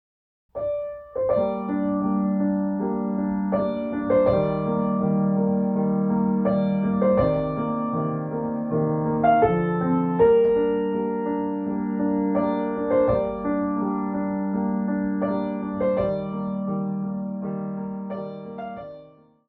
Piano Solo
10 minimalist pieces for Piano.